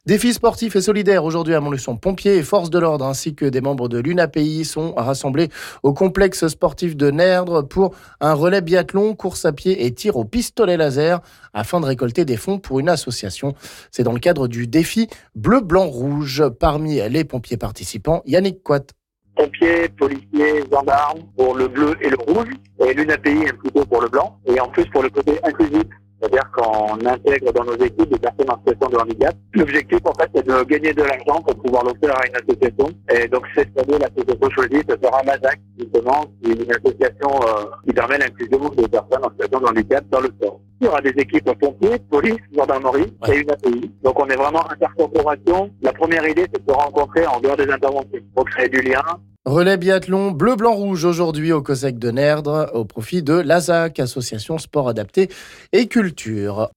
Parmi les pompiers participants on écoute